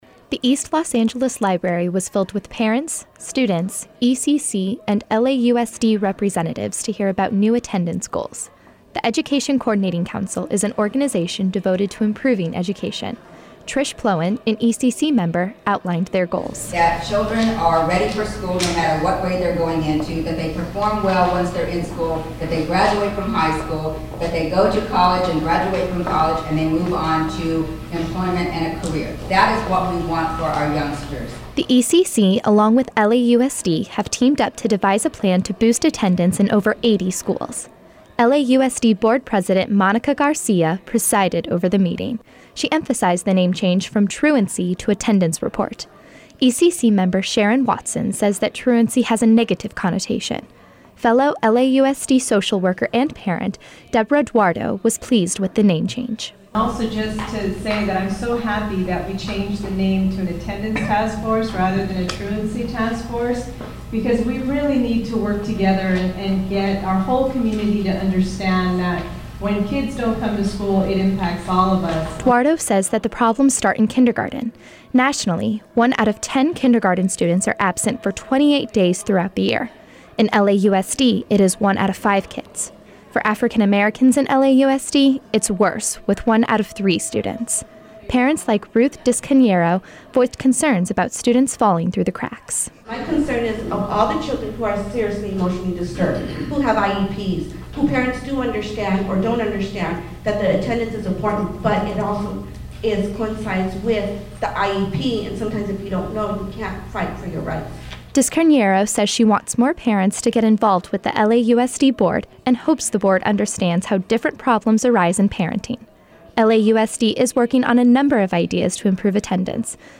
The East Los Angeles Library was filled with parents, students, ECC and LAUSD representatives to hear about new attendance goals.